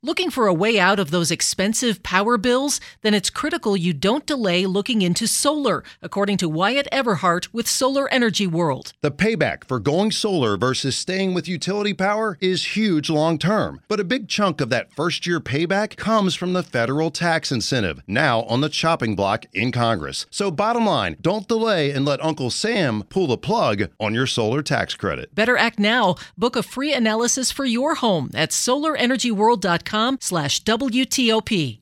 is answering some of the most pressing questions about shifting to solar power in 2025 in the new 5-part WTOP interview series below.